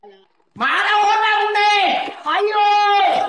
ayooo sayip op Meme Sound Effect
Category: Reactions Soundboard